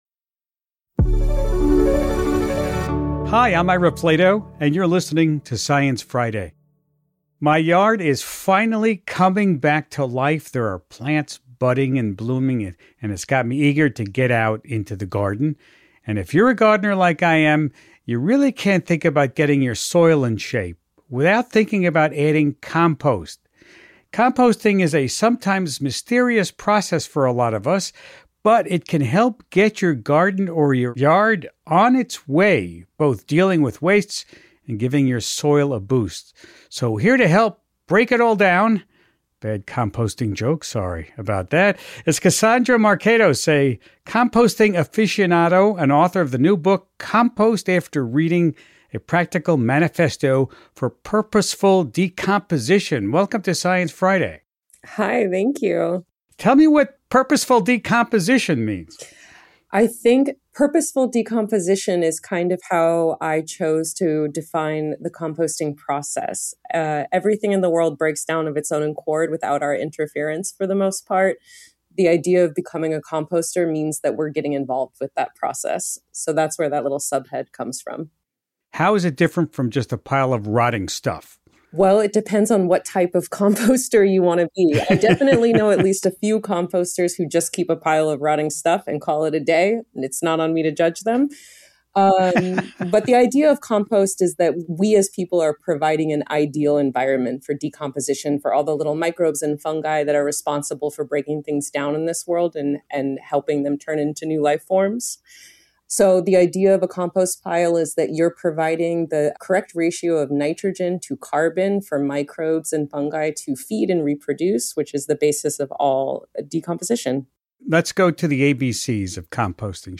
Host Ira Flatow